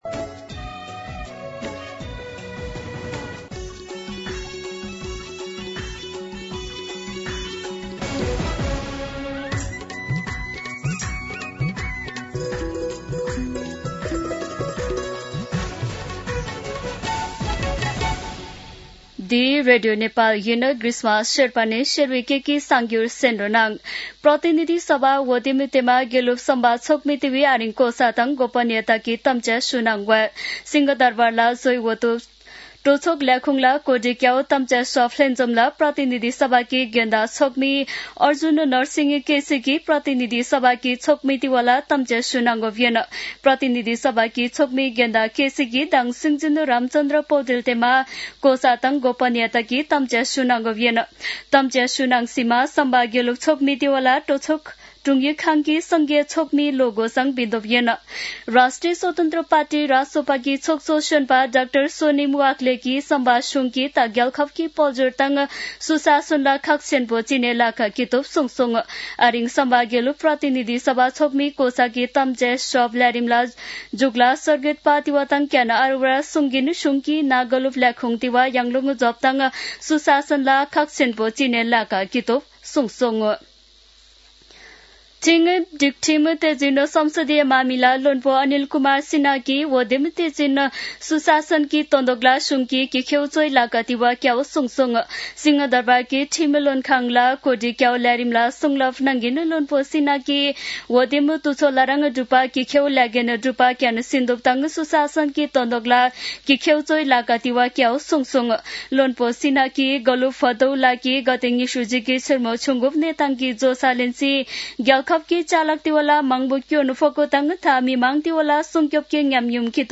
शेर्पा भाषाको समाचार : १२ चैत , २०८२